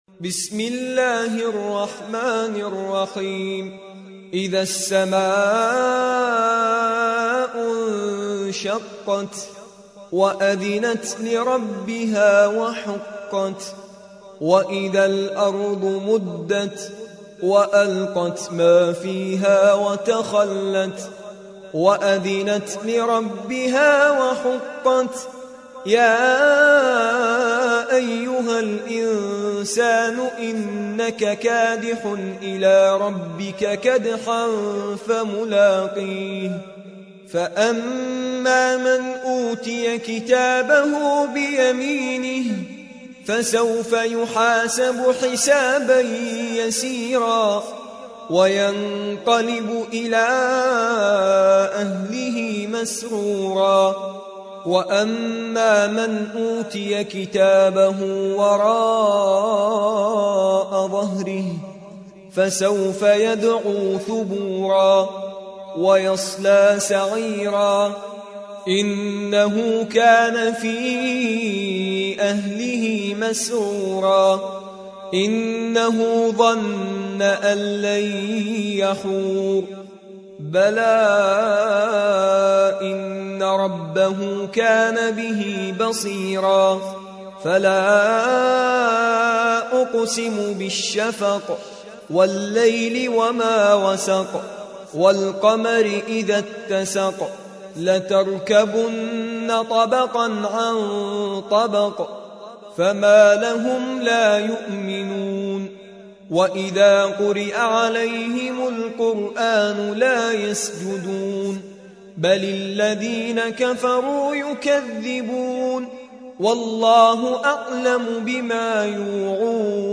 84. سورة الانشقاق / القارئ